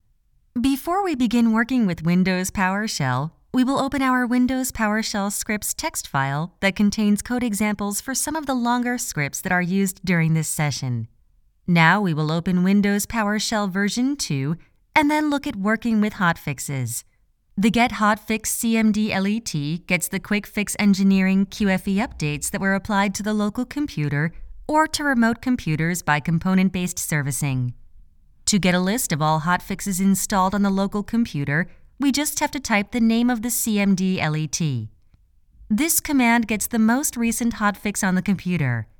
Female
My vocal range extends from a 20's bright and bubbly to a 40's warmly confident.
Explainer Videos